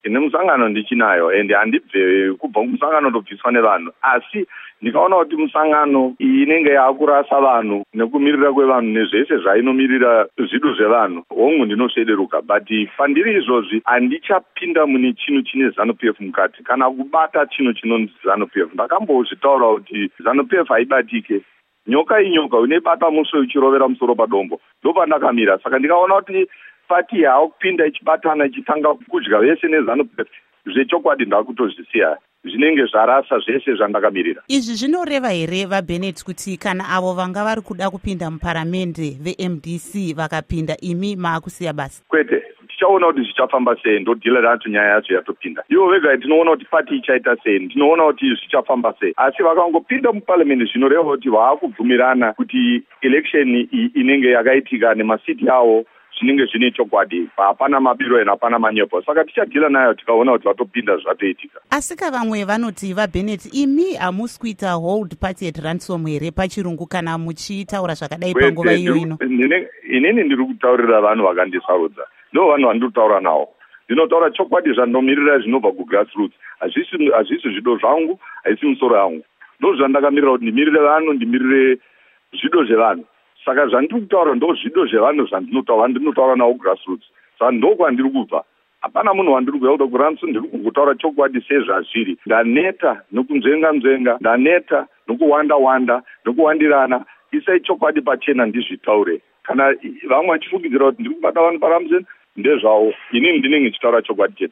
Hurukuro NaRoy Bennet